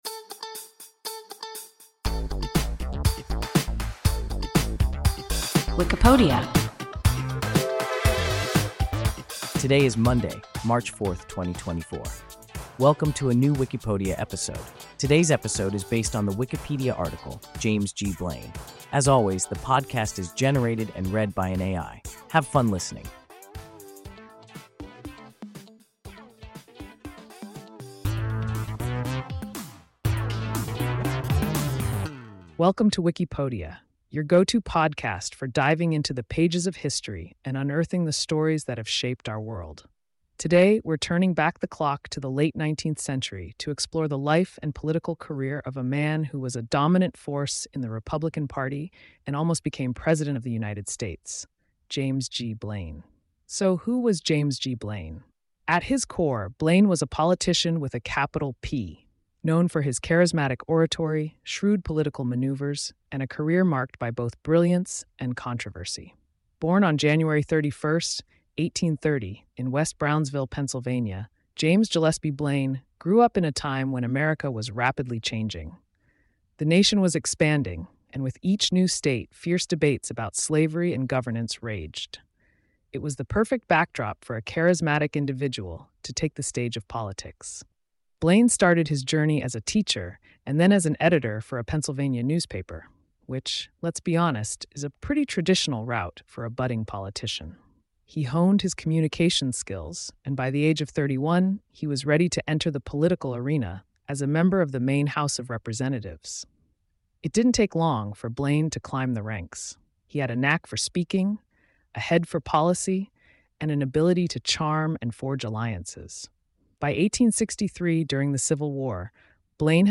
James G. Blaine – WIKIPODIA – ein KI Podcast